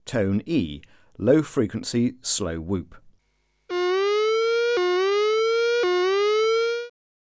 Alert Tone: E